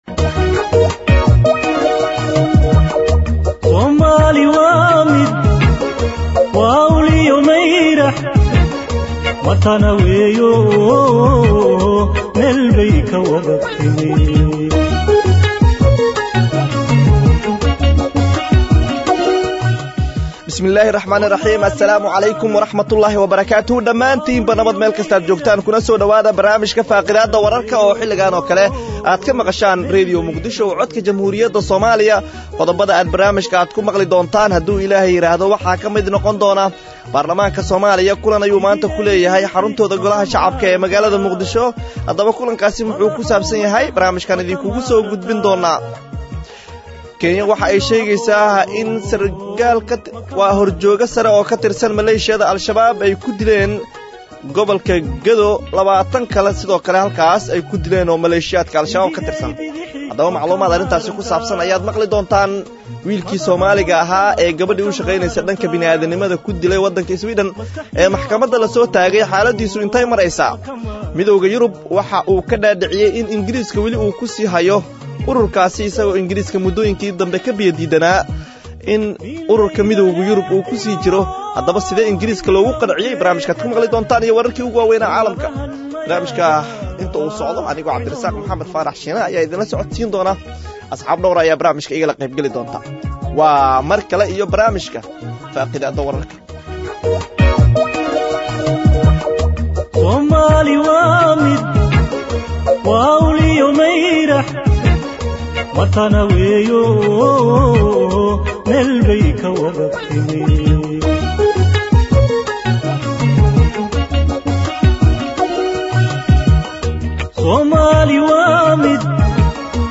Akhristayaasheena sharafta leh waxaan halkaan idinkugu soo gudbineynaa barnaamijka Faaqidaada oo ka baxa Radio Muqdisho subax waliba marka laga reebo subaxda Jimcaha, waxaana uu xambaarsanyahay macluumaad u badan wareysiyo iyo falaqeyn xagga wararka ka baxa Idaacadda, kuwooda ugu xiisaha badan.